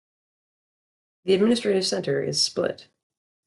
Pronounced as (IPA) /splɪt/